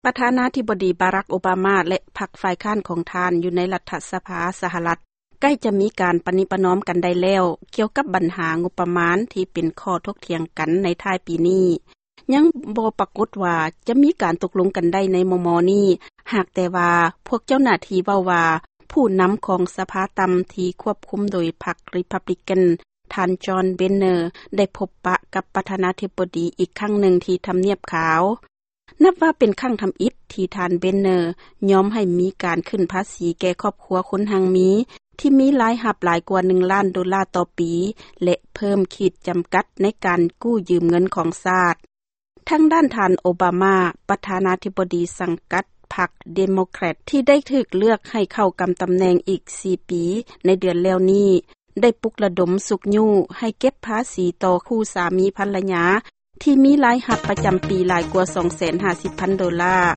ຟັງຂ່າວການເຈລະຈາງົບປະມານສະຫະລັດ